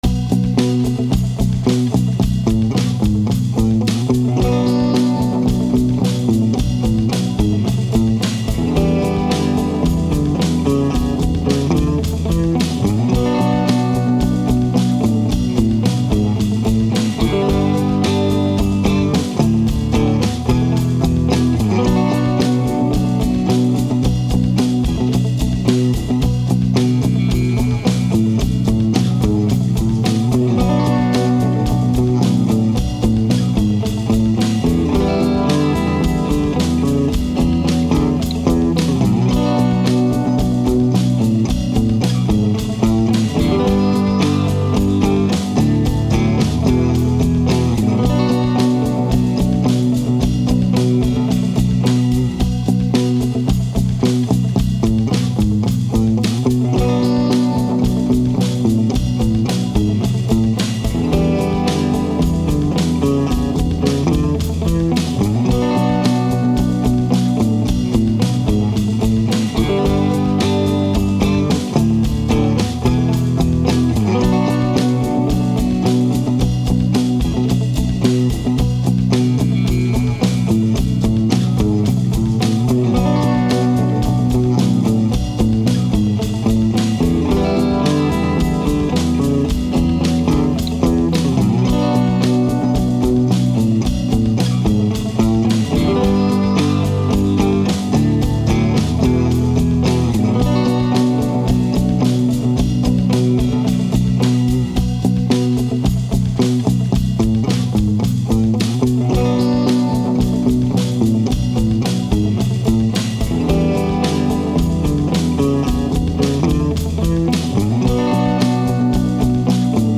Jam Track: Funky 12-bar Blues in Bm
The Custom 45 has a really beefy low-end and a slight scooped tone, and the Messenger, while a baritone, has this incredibly bright-sounding voice.
For the rhythm part, I used a fairly basic rock beat, but I also added some Latin drums underneath to take the edge off the heavy downbeat. And by the way, there’s no bass in this track at all.